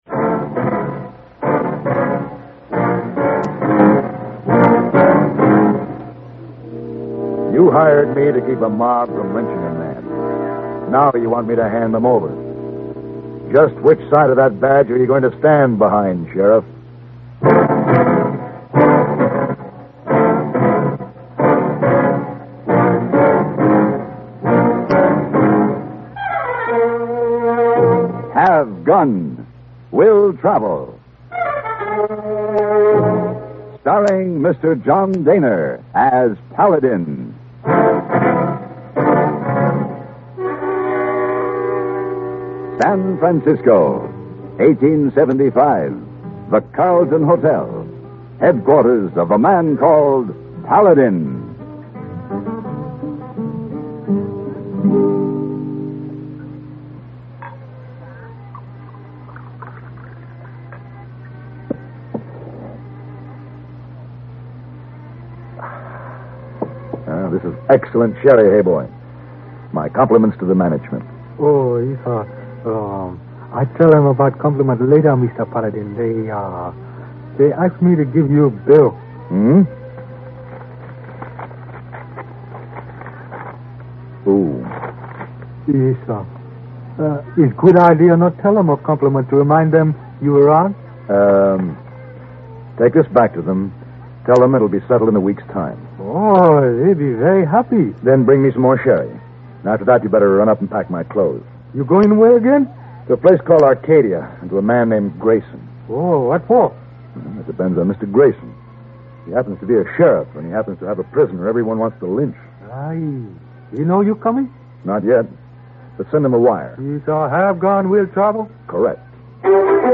Will Travel Radio Program
Starring John Dehner